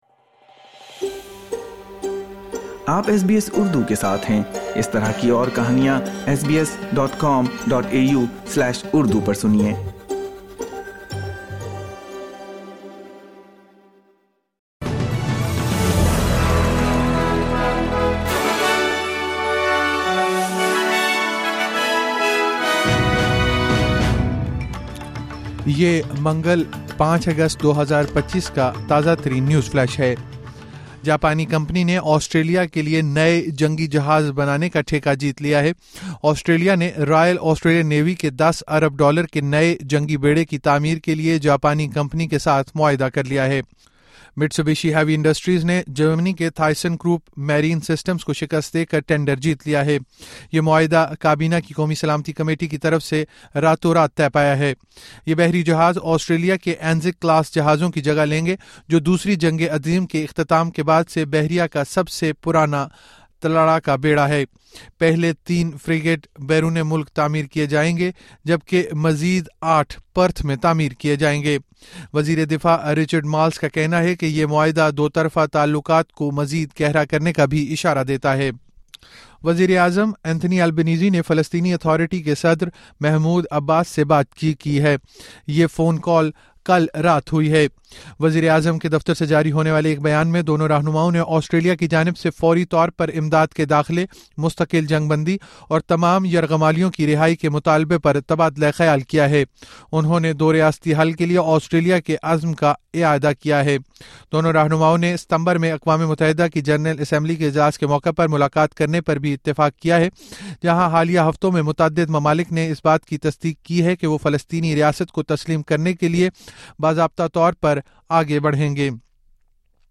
مختصر خبریں: منگل 05 اگست 2025